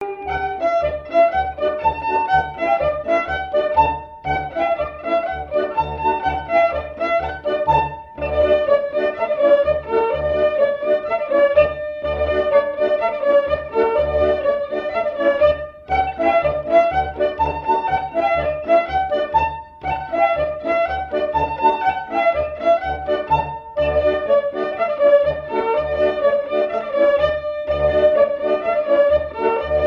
Marais Breton Vendéen
danse : branle : courante, maraîchine
Pièce musicale éditée